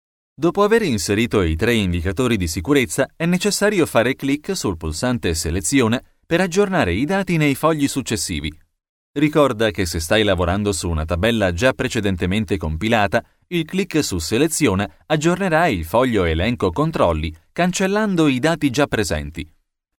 Kein Dialekt
Sprechprobe: eLearning (Muttersprache):
Studio equipment: Professional Recording Sound Booth; Microphone Neumann TLM 103; Pre-amp JoeMeek ThreeQ; Soundcard Apogee Duet; iMac